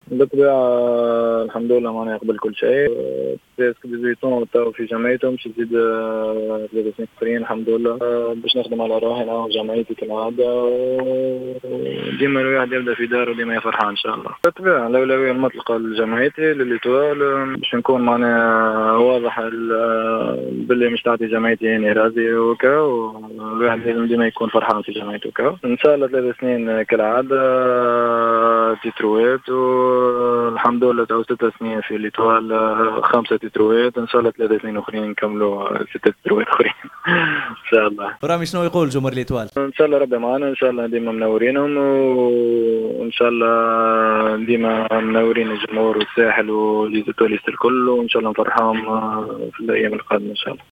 في تصريح لجوهرة اف ام